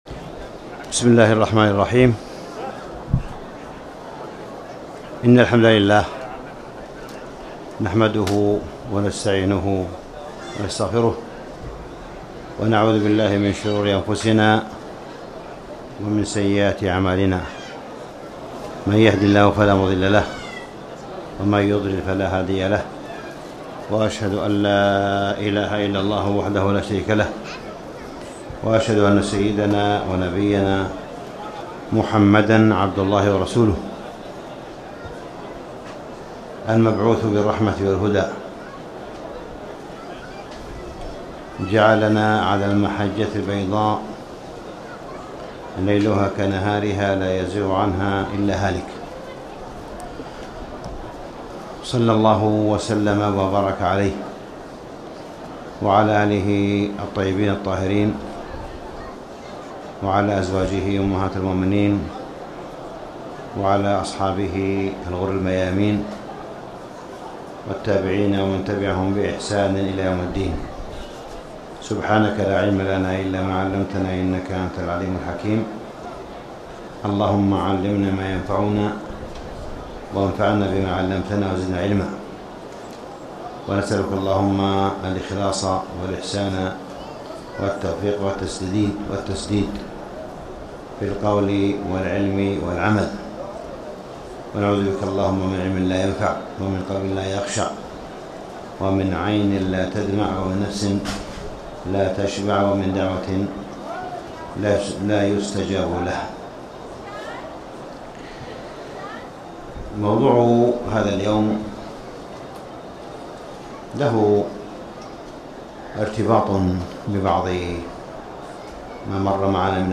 تاريخ النشر ١٦ رمضان ١٤٣٨ هـ المكان: المسجد الحرام الشيخ: معالي الشيخ أ.د. صالح بن عبدالله بن حميد معالي الشيخ أ.د. صالح بن عبدالله بن حميد هدي النبي صلى الله عليه وسلم مع غير المسلم